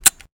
revolver_A_empty.wav